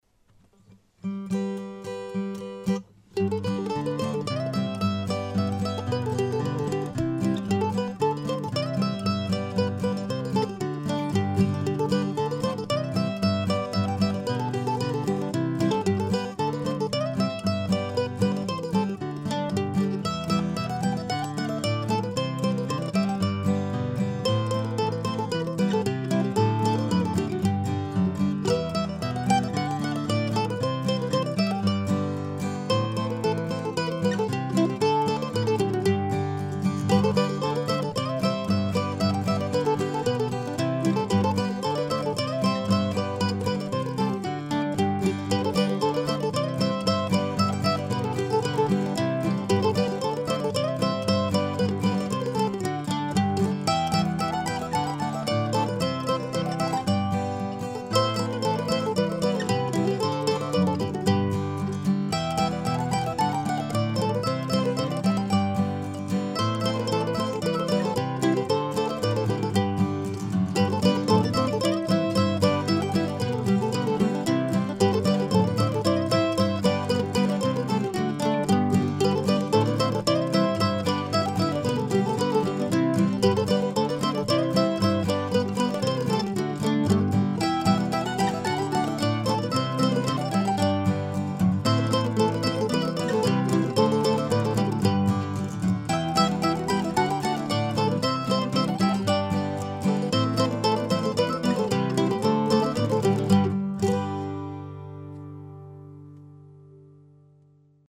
Dodge the Plow ( mp3 )( pdf ) This tune comes from sometime last winter when there were plenty of snow plows out keeping our roads clear and safe for travel. I added a bit of harmony on the second time around and overdubbed another mando playing the melody (mostly) an octave down on the third pass.